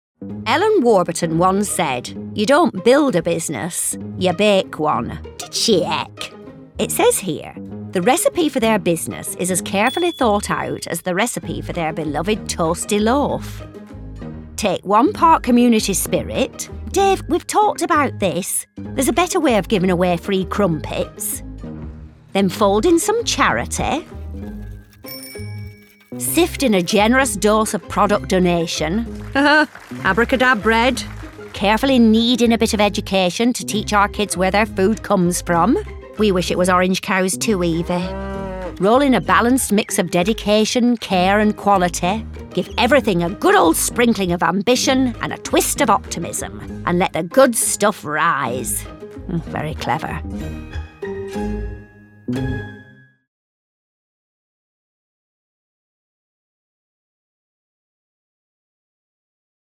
Lancashire
Northern
Female
Bright
Characterful
Distinctive
WARBURTONS COMMERCIAL
Jane Horrocks_Warburtons Commercial 3_United Voices.mp3